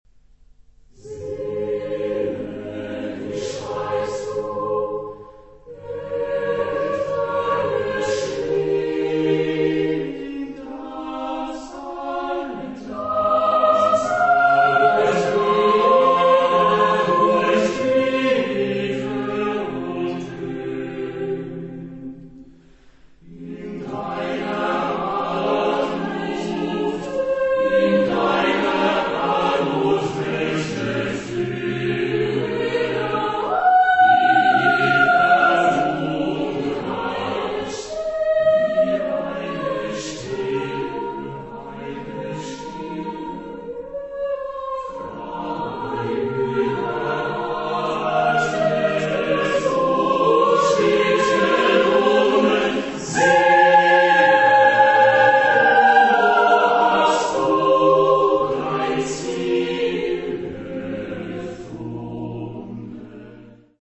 Genre-Stil-Form: Volkstümlich
Charakter des Stückes: ruhig
Chorgattung: SATB  (4 gemischter Chor Stimmen )
Tonart(en): As-Dur